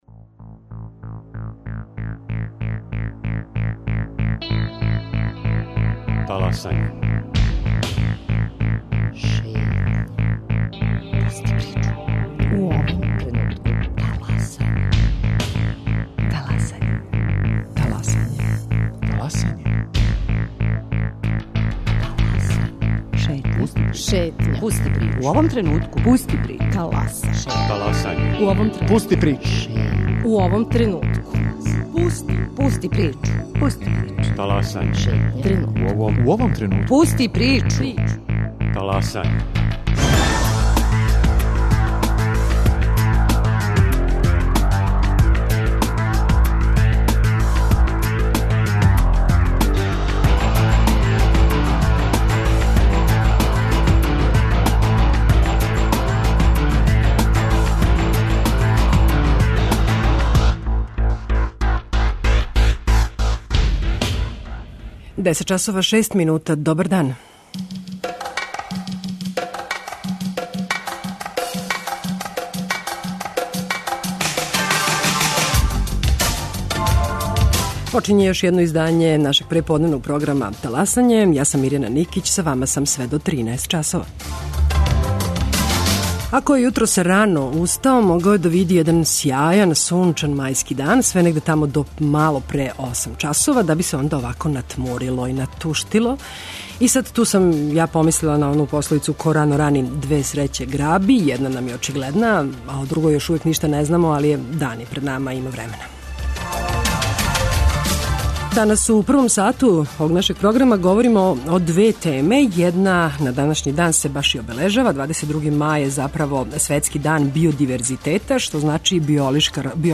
Гости Таласања говориће о биодиверзитету Србије и напорима да се сачува природно велика разноврсност биљног и животињског света на нашим просторима.